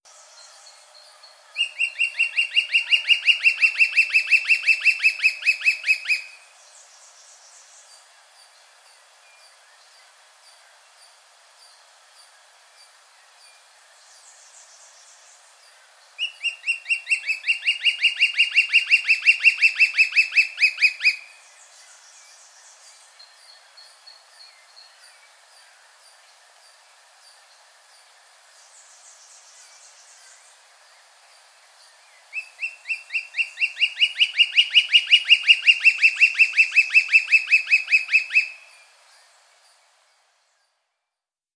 Dendrocolaptes platyrostris - Trepador oscuro.wav